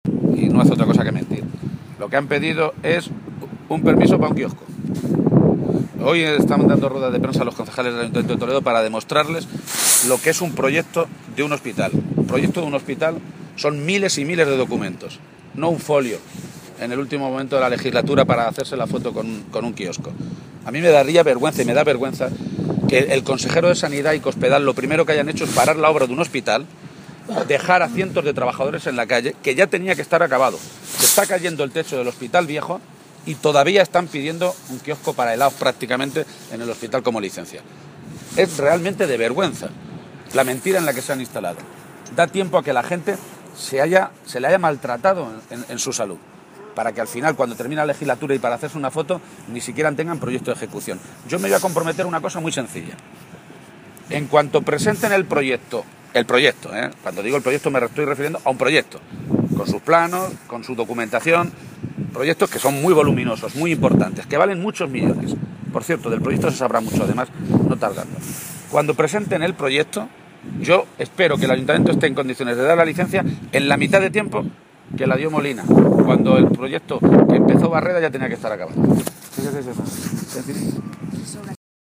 García-Page se pronunciaba de esta manera esta mañana, en Cuenca, en una comparecencia ante los medios de comunicación, en la que aseguraba que lo más triste de este tipo de cosas (derrumbes de techos de hospitales y despidos como ejemplo del desmantelamiento de los servicios públicos esenciales) es que han ocurrido no solo después de que Cospedal prometiera que no iba a traspasar las líneas rojas de sus recortes en la Sanidad o la Educación, sino “después de que ver cómo vamos a terminar la legislatura con 6.000 millones de euros más de deuda pública. Es decir, que con Cospedal hemos acumulado más deuda pública en tres años que en los treinta anteriores de la historia” de Castilla-La Mancha.